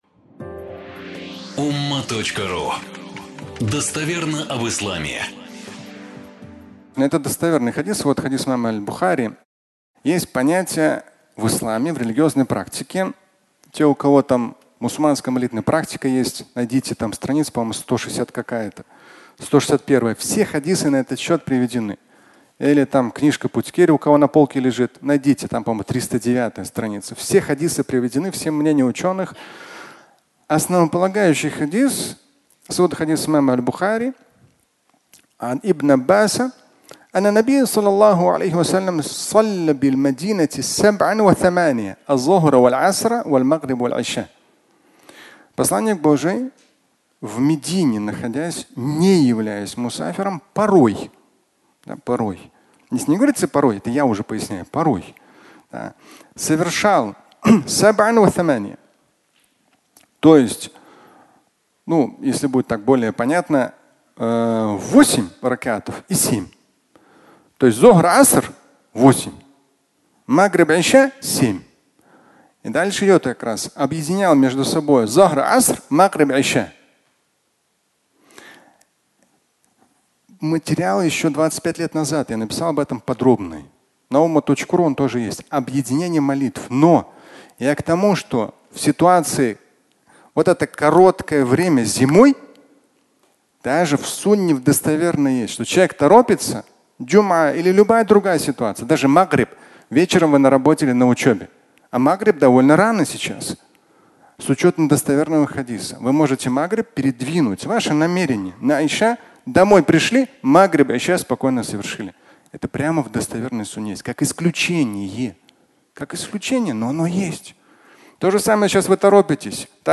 Объединение молитв (аудиолекция)